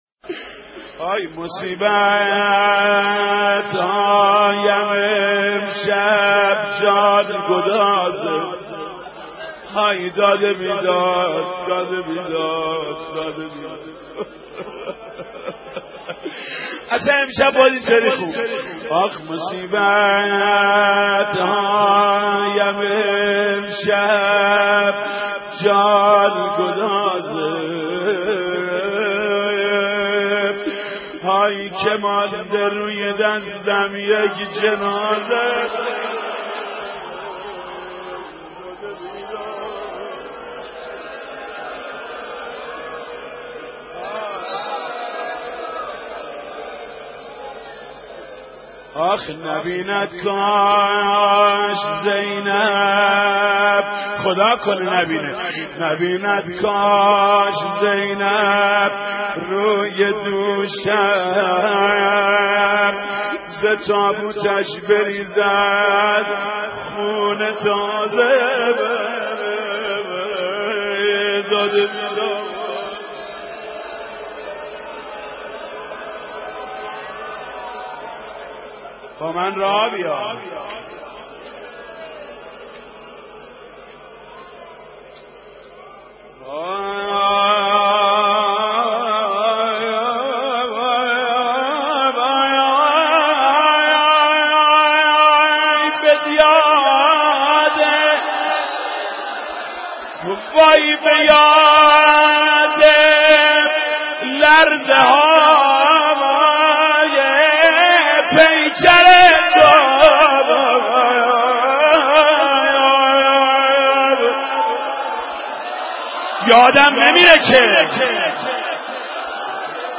روضه خوانی «محمود کریمی» در سوگ شهادت حضرت فاطمه زهرا(س)(2:41)